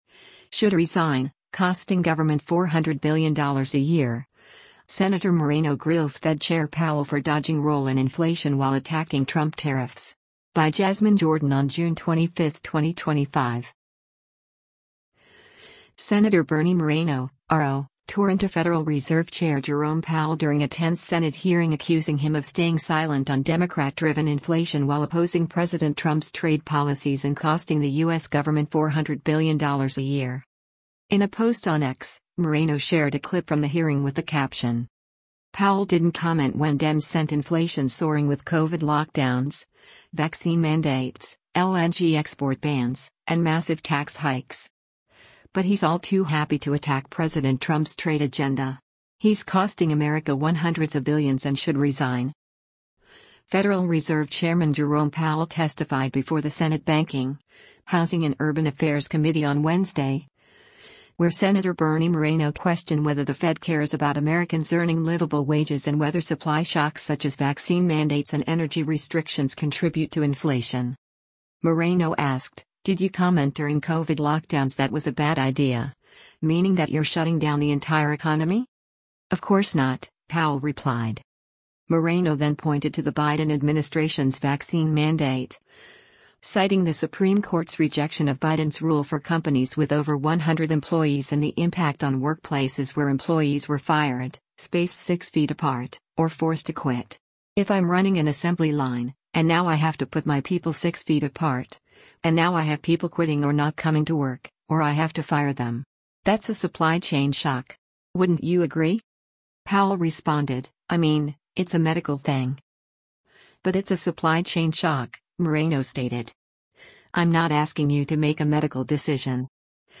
Sen. Bernie Moreno (R-OH) tore into Federal Reserve Chair Jerome Powell during a tense Senate hearing accusing him of staying silent on Democrat-driven inflation while opposing President Trump’s trade policies and costing the U.S. government $400 billion a year.
Federal Reserve Chairman Jerome Powell testified before the Senate Banking, Housing and Urban Affairs Committee on Wednesday, where Sen. Bernie Moreno questioned whether the Fed cares about Americans earning livable wages and whether supply shocks such as vaccine mandates and energy restrictions contribute to inflation.